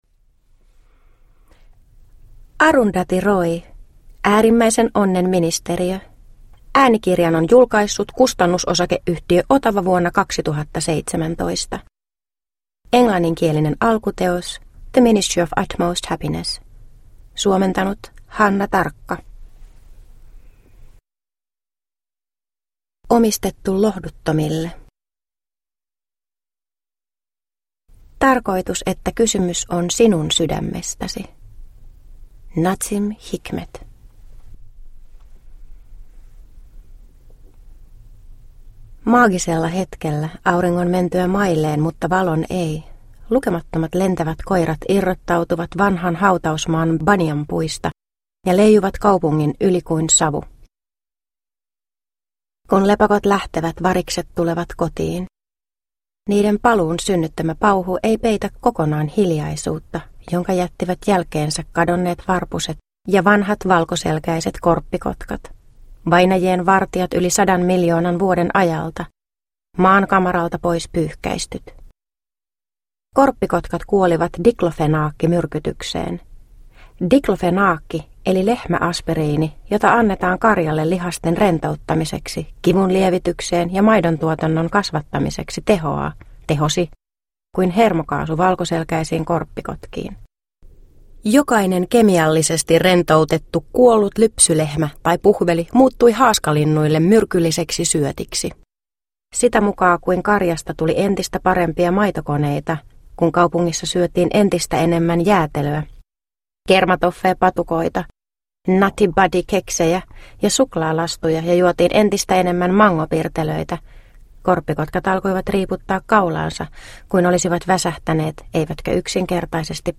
Äärimmäisen onnen ministeriö – Ljudbok – Laddas ner